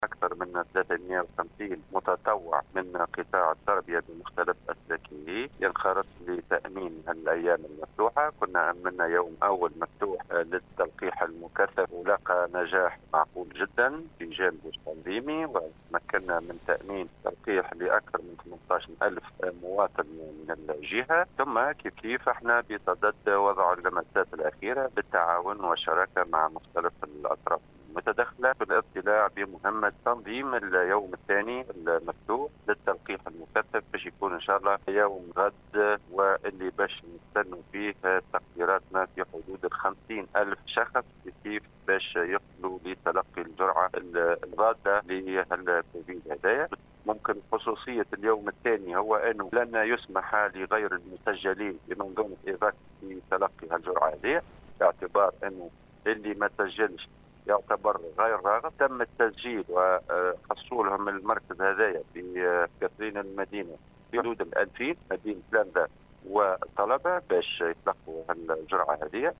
صرّح المندوب الجهوي للتربية بالقصرين المنصف القاسمي لاذاعة السيليوم أف أم اليوم السّبت 14 أوت 2021 إنّ أكثر من 350 متطوّع من قطاع التربية بمختلف أسلاكه سينخرط في تأمين الأيّام المفتوحة لتلقي التلقيح غدا الأحد .